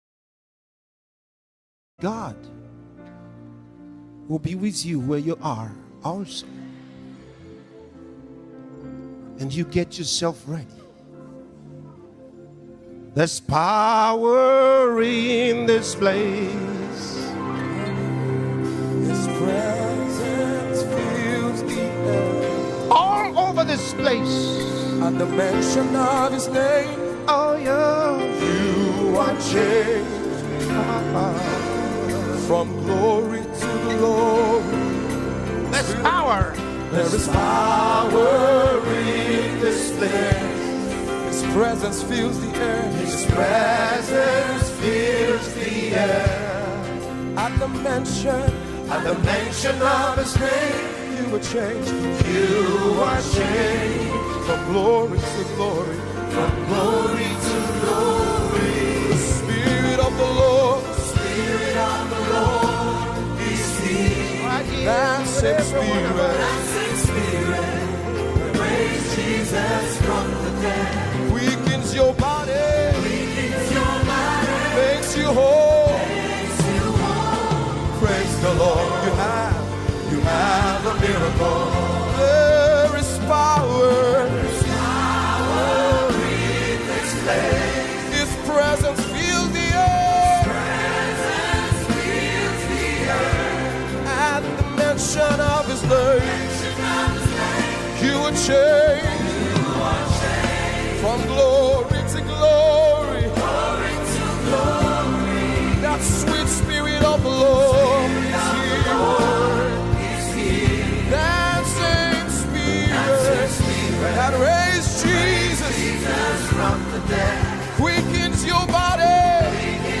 Glorious melodies